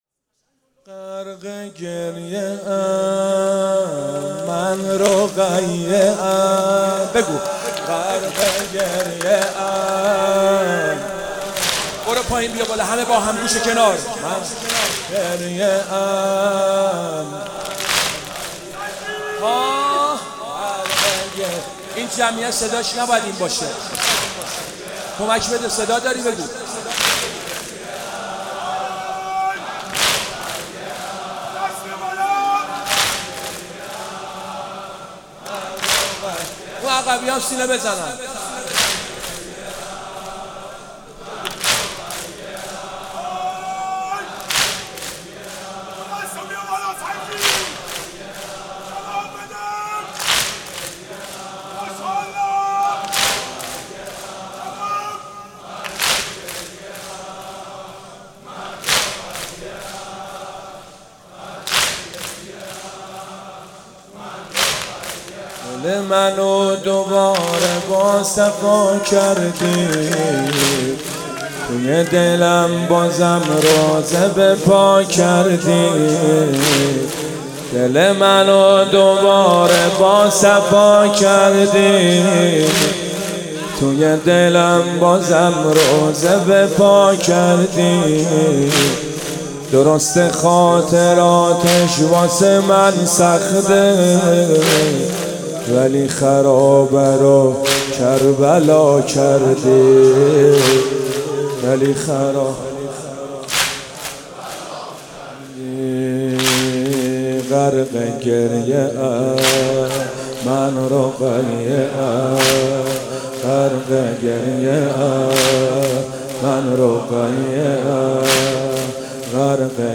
مناسبت : شب سوم محرم